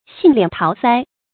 杏脸桃腮 xìng liǎn táo sāi
杏脸桃腮发音